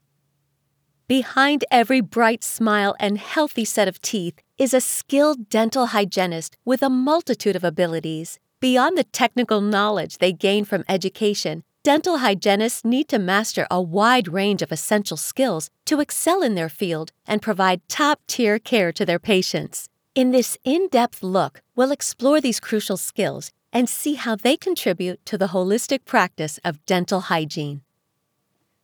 Female
English (North American)
Adult (30-50), Older Sound (50+)
E-Learning
Elearning Sample, Dental
0501Elearning_DentalHygienist.mp3